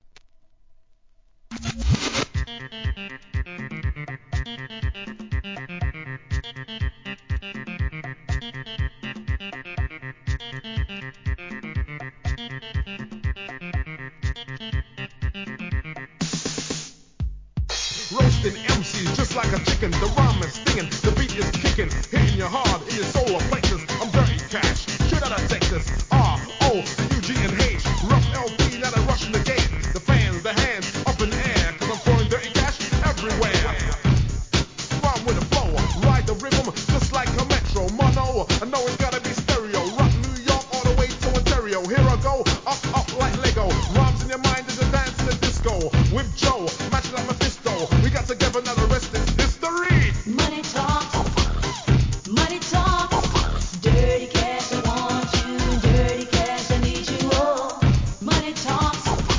HIP HOUSE